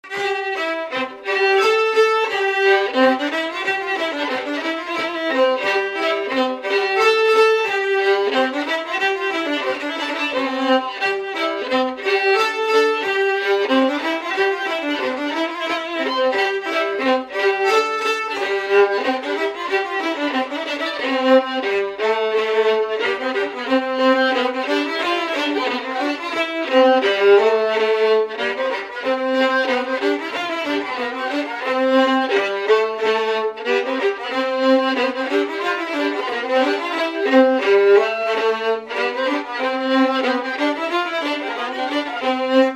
Chants brefs - A danser
Résumé instrumental
danse : scottish (autres)
Catégorie Pièce musicale inédite